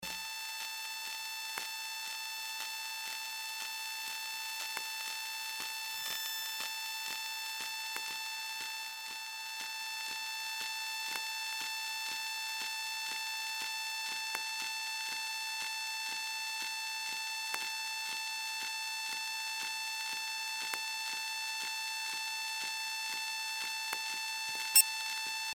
Звуки экшн-камеры
Электронные помехи спортивной камеры